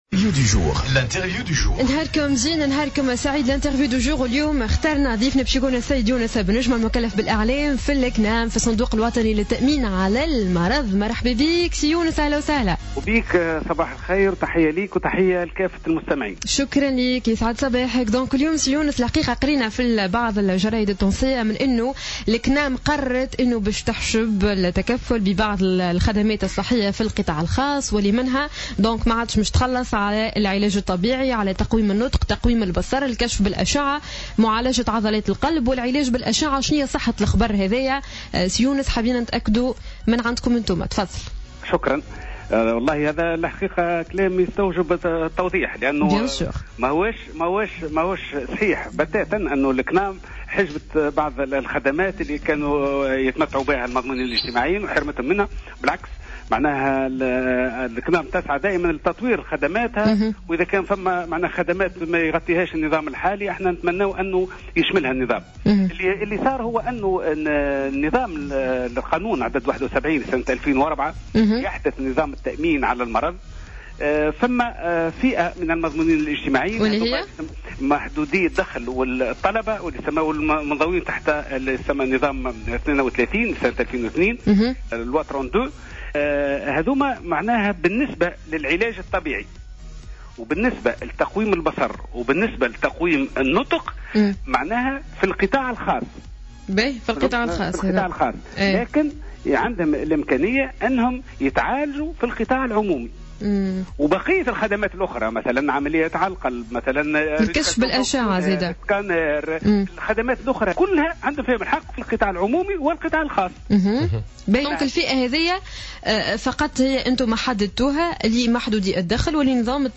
في مداخلة له على الجوهرة "اف ام"